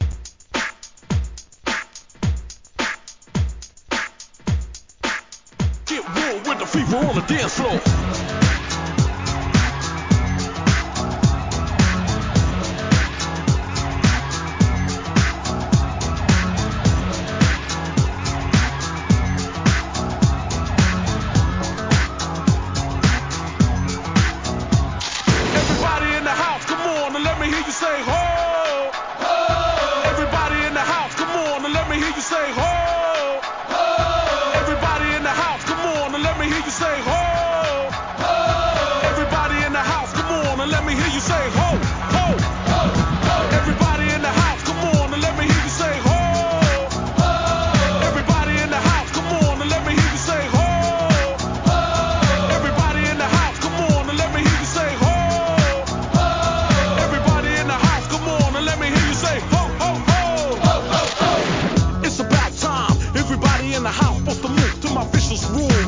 HIP HOP/R&B
(107 BPM)